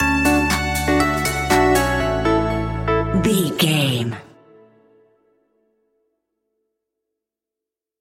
Upbeat Groove Electro Stinger.
Ionian/Major
groovy
energetic
uplifting
electric guitar
bass guitar
drum machine
synthesiser
funky house
electro